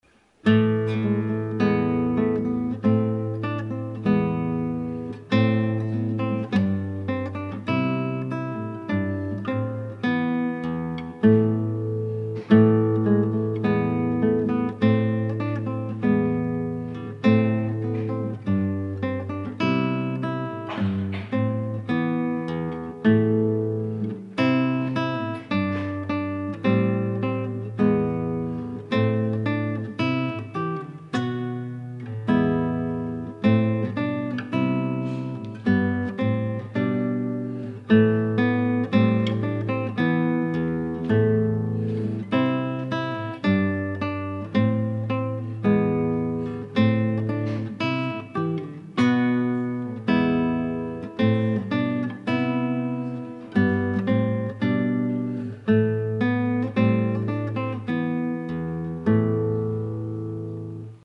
- Guitare Classique
C'est une pièce anonyme du 16 ème siècle dont le thème a été souvent reprit; entre autre par John Dowland, la tonalité est La mineur avec une modulation très courte en Mi majeur à la 9ème mesure.
Ensuite la présence du Sol#, indique bien que la tonalité LA mineur est basé sur la gamme de LA mineur harmonique(La Si Do Ré Mi Fa Sol#) donc en utilisant le 5 ème degré de cette gamme on peut former l'accord de MI (Mi Sol# Si) qui va être très présent dans la pièce qui utilise une progression I-V La m Mi avec quelques détour léger.
L'utilisation de la métrique 2/2 est très intéressante pour ceux qui commence à découvrir cette parite du répertoire.